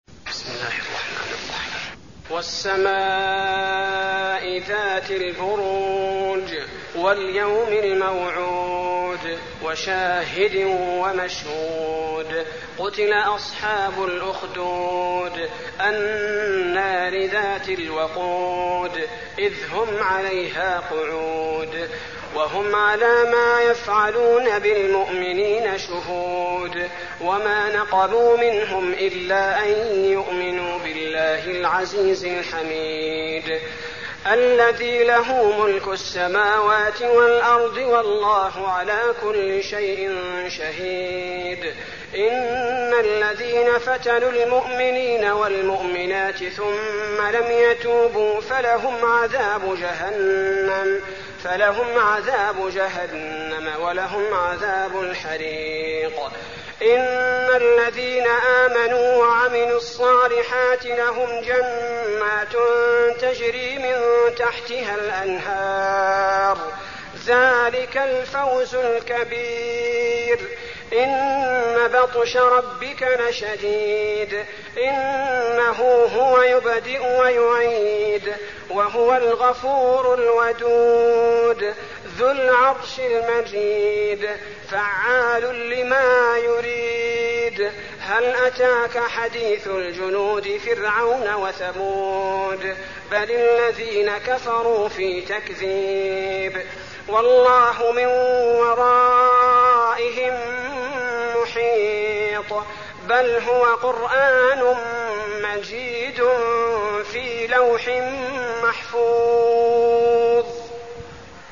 المكان: المسجد النبوي البروج